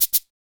washboard_dd.ogg